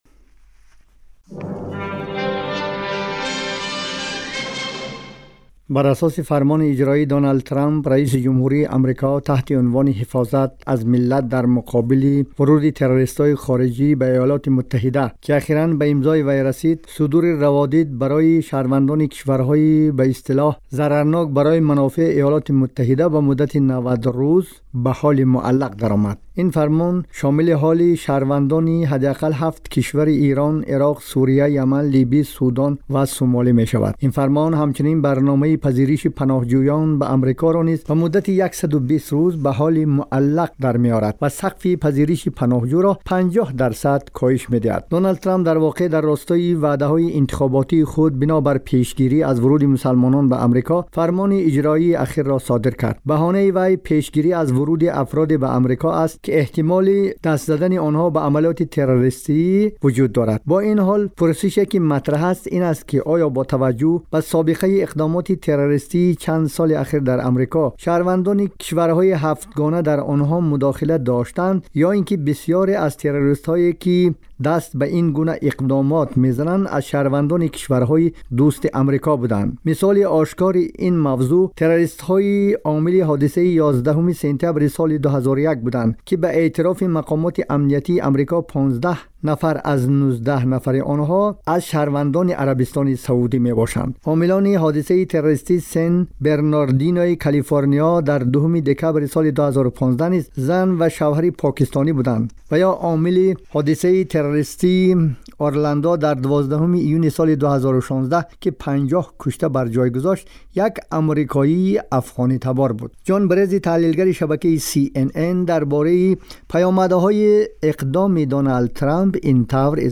дар гузориши вижа ба ин мавзӯъ пардохтааст, ки дар идома мешунавед: